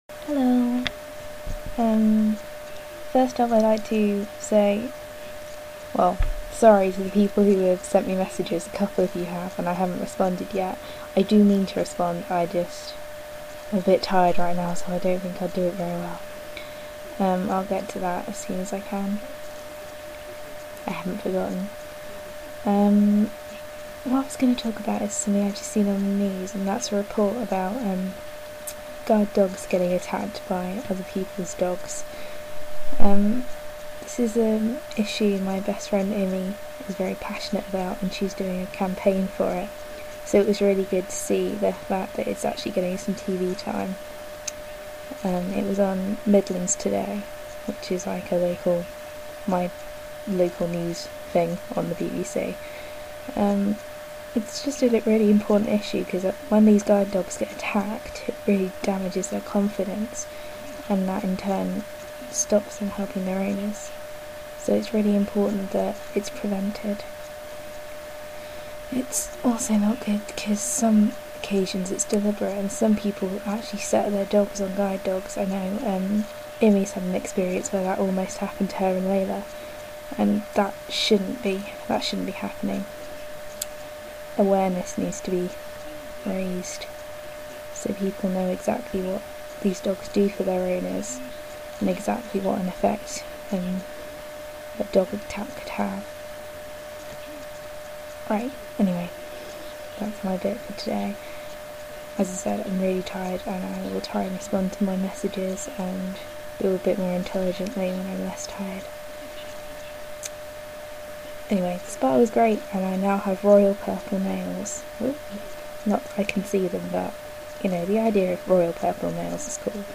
Sorry, my voice is very quiet and the background noise is a bit loud.